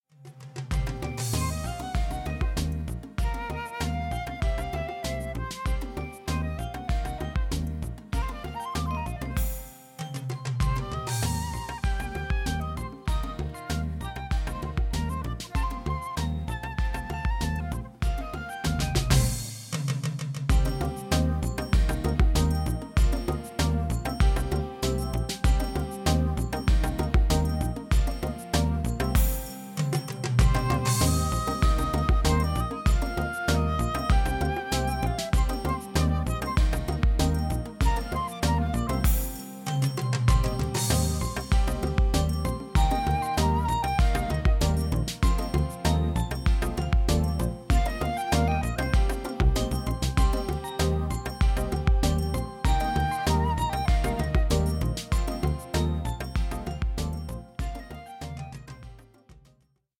Das Playback-Album zur gleichnamigen Produktion.
Playback ohne Backings, gleiche Reihe 2,99 €